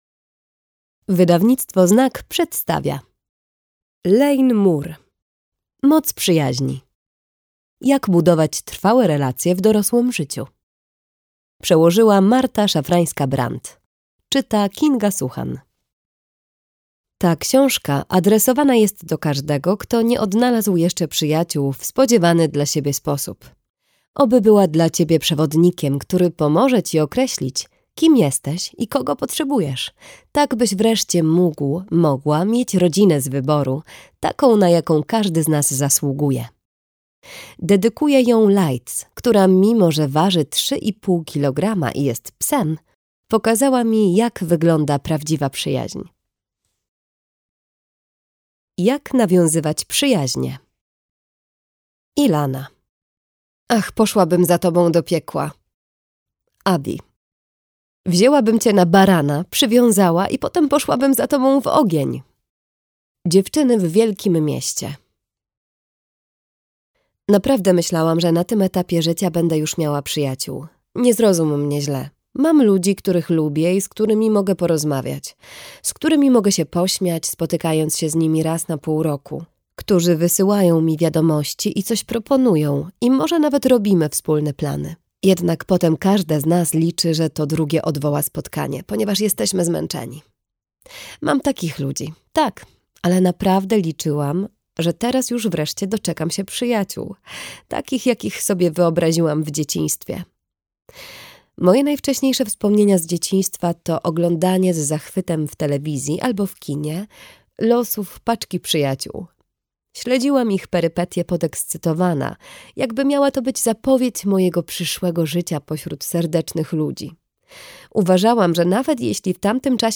Jak budować trwałe relacje w dorosłym życiu - Moore Lane - audiobook - Legimi online